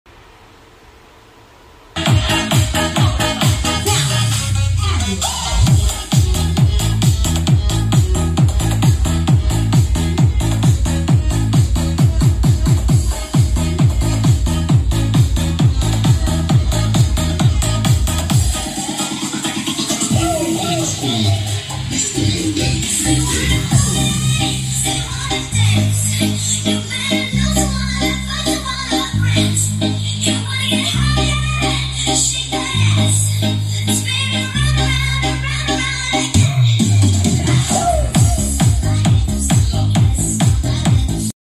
Actual sounds of Partybox 215 sound effects free download